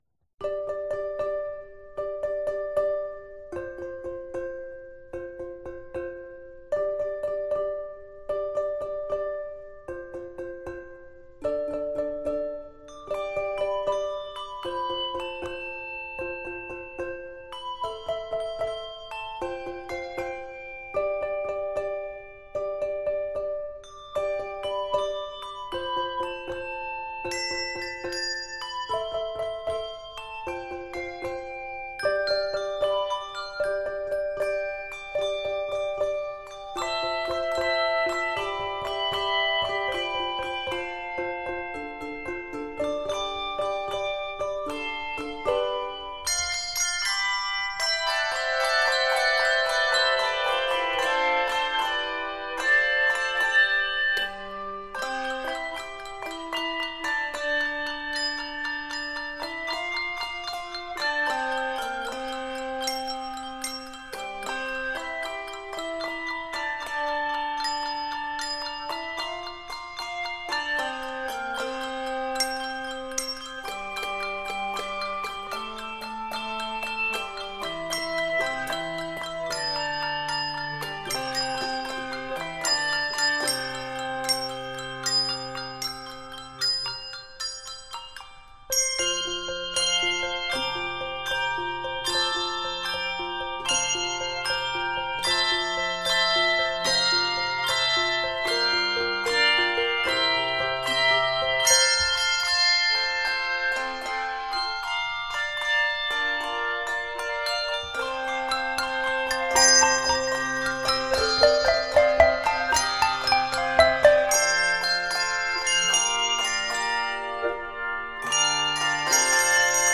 Using mallets and a compound meter
Celtic flare